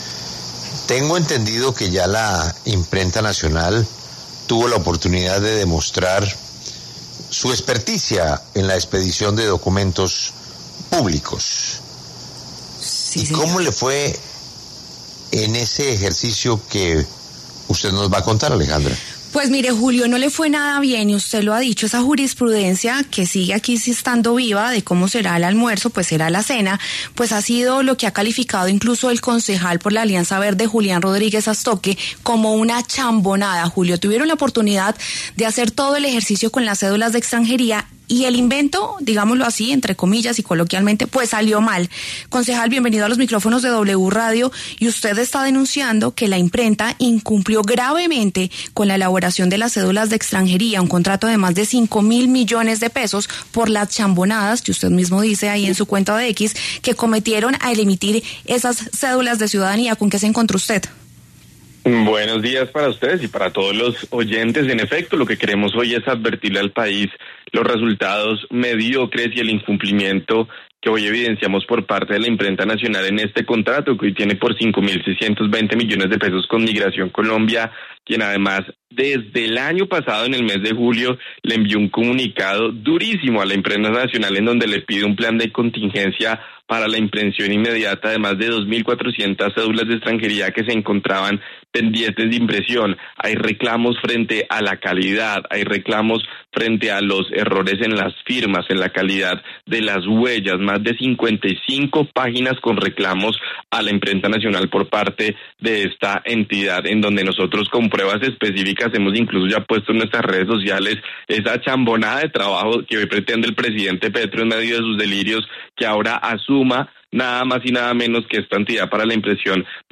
Ante esa nueva responsabilidad de la Imprenta, el concejal por Bogotá de la Alianza Verde, Julián Rodríguez Sastoque, denunció en los micrófonos de W Radio las “chambonadas” que cometió la entidad del Gobierno a la hora de expedir las cédulas de extranjería durante el último año, y que se convertía en la primera muestra de las capacidades de la Imprenta Nacional.